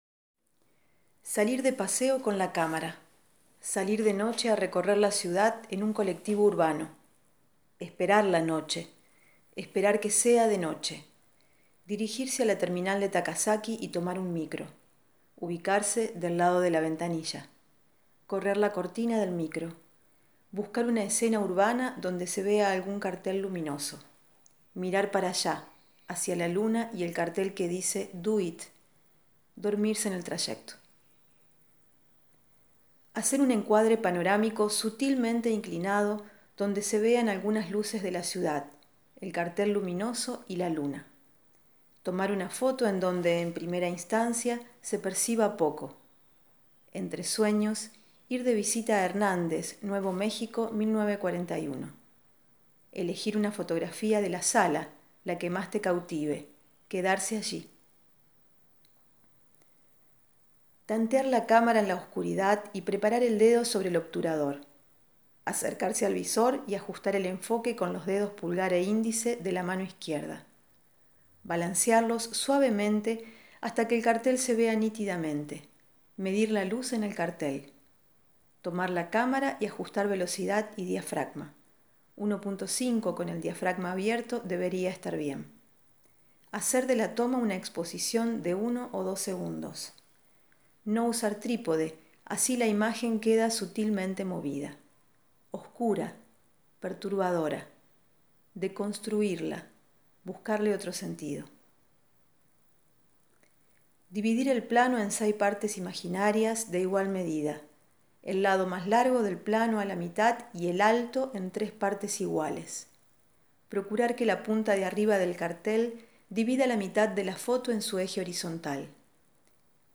le pone voz a esos textos, que viajan como mensajes de audio por wasapp.